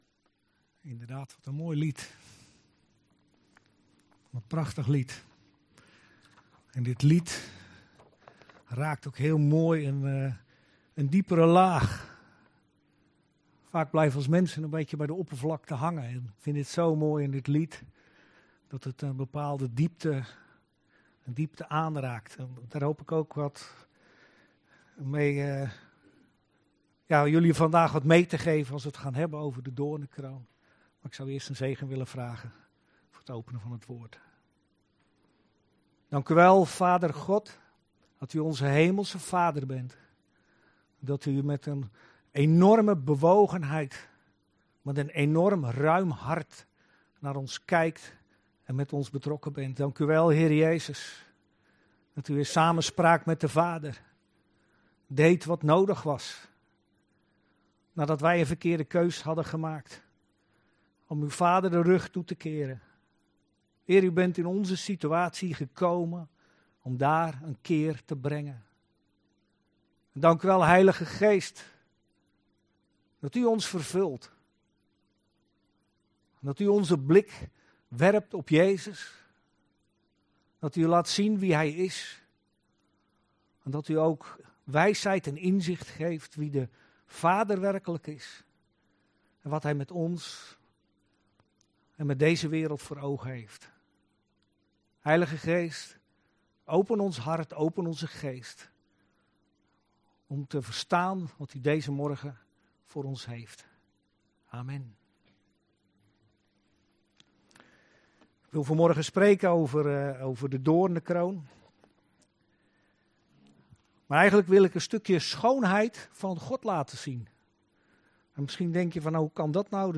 Preek beluisteren - evangelische gemeente De Bazuin